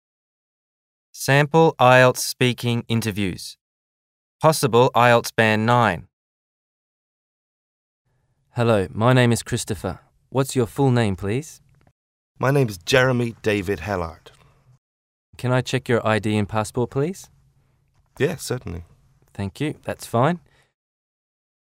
Sample IELTS Speaking Interviews